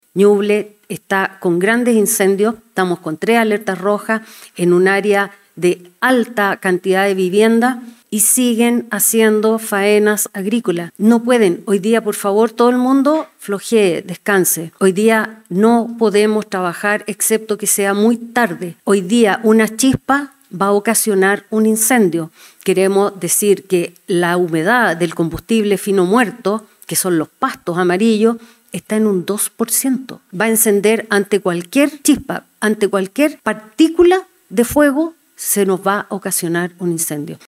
La Directora Nacional de Conaf reiteró el llamado a no realizar faenas agrícolas.